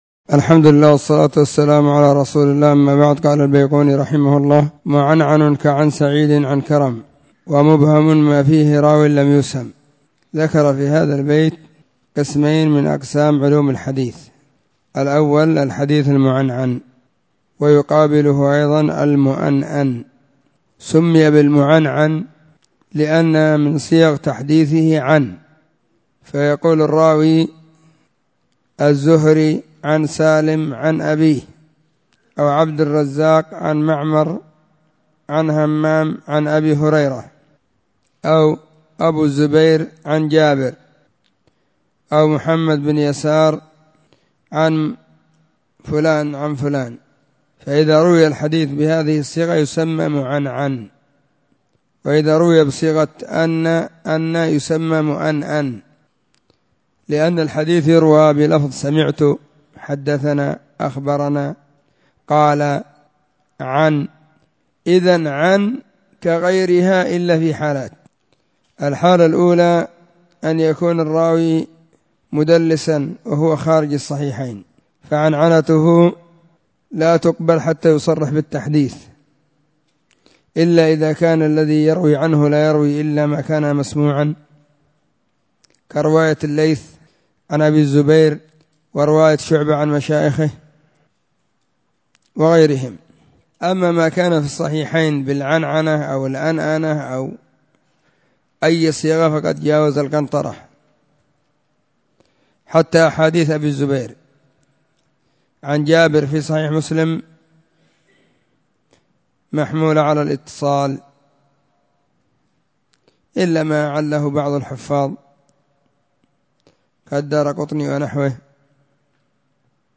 الدرس 11- من التعليق المختصر على المنظومة البيقونية.
📢 مسجد الصحابة – بالغيضة – المهرة، اليمن حرسها الله.